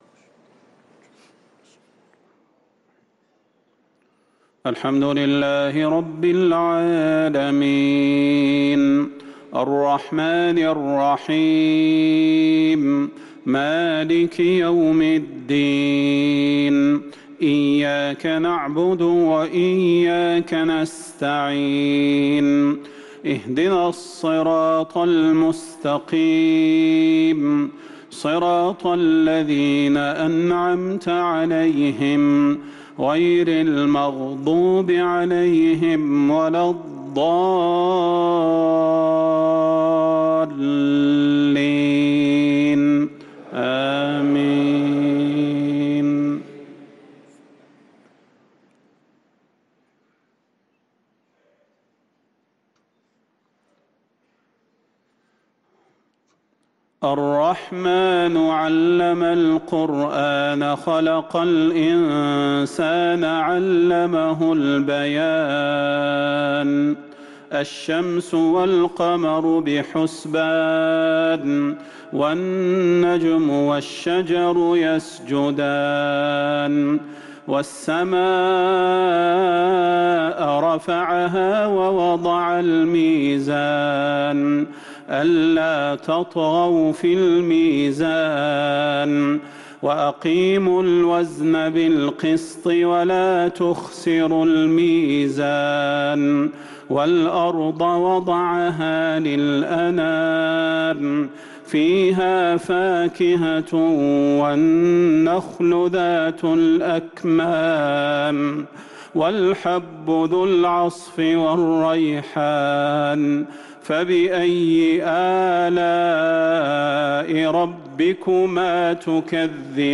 صلاة العشاء للقارئ صلاح البدير 25 ربيع الأول 1445 هـ